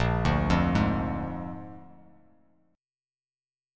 Bb7 Chord
Listen to Bb7 strummed